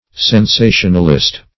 Sensationalist \Sen*sa"tion*al*ist\, n.